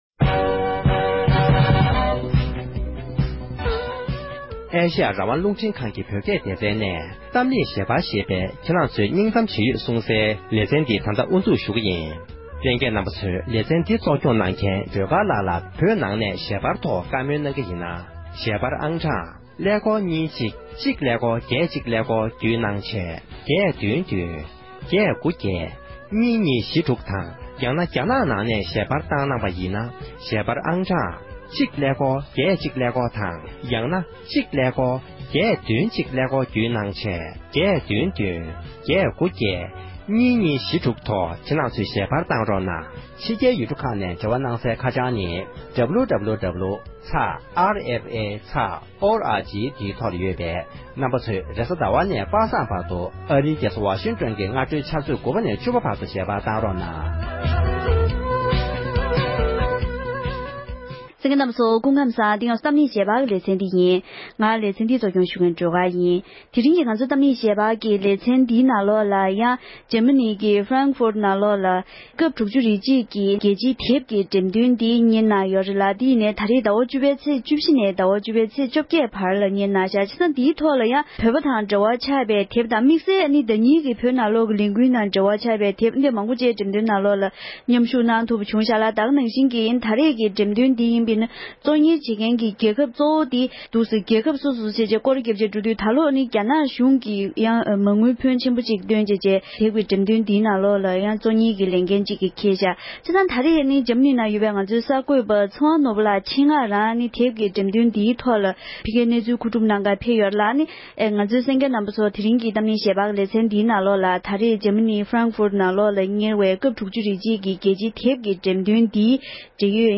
འབྲེལ་ཡོད་མི་སྣ་ཁག་དང་ལྷན་དུ་བགྲོ་གླེང་ཞུས་པ་ཞིག་ལ་གསན་རོགས༎